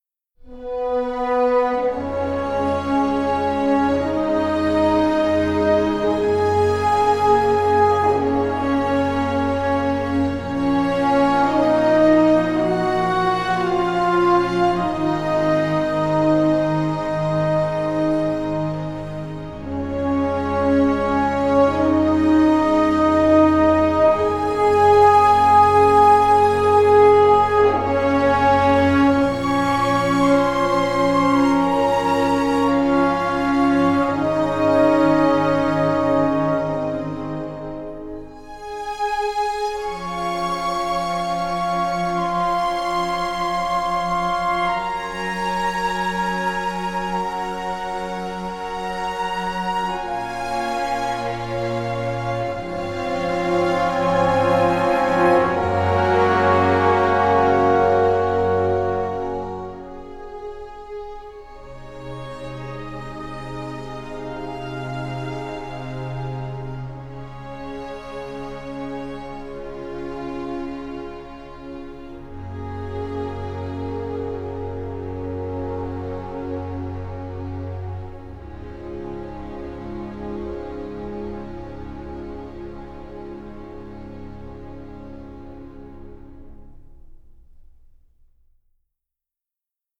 orchestral score which offers extremely wide range of colors